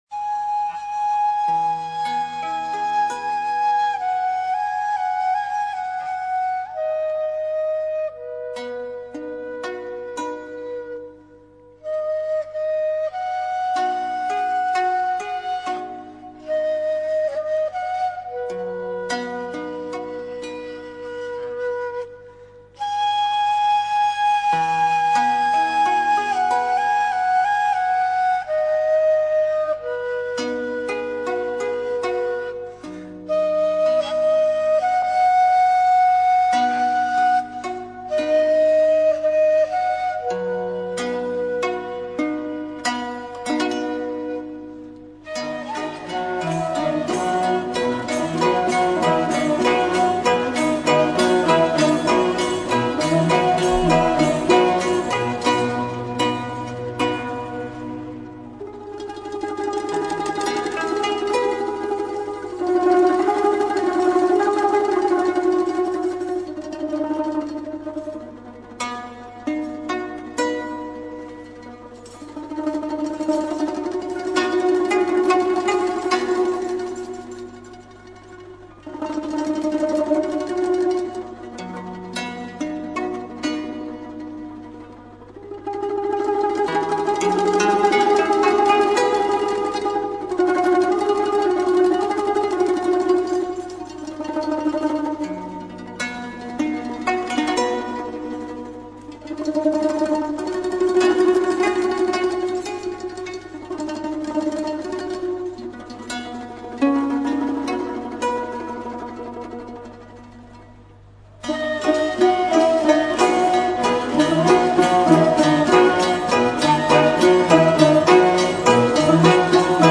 Кюи [13]
Композиция его состоит из двух частей контрастного характера. За первой частью - медленной, певучей следует быстрая, танцевальная, ликующего характера.